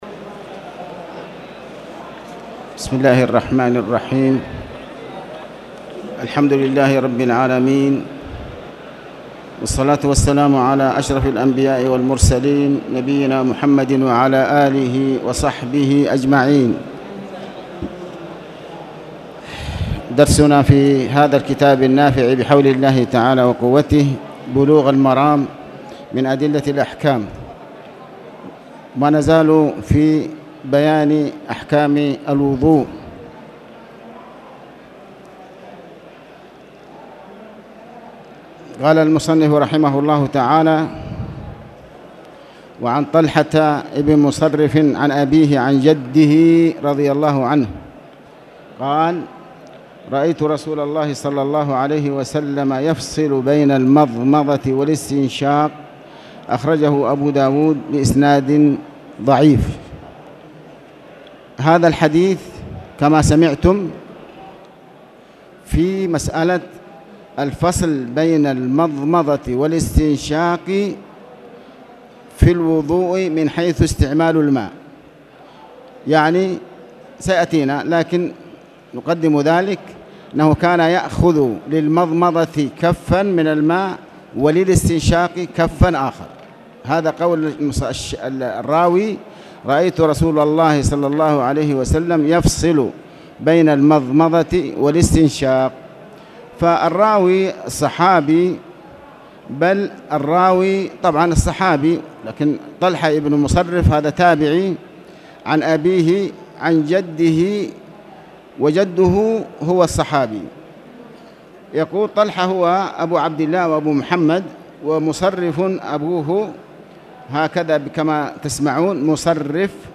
تاريخ النشر ١٩ جمادى الأولى ١٤٣٨ هـ المكان: المسجد الحرام الشيخ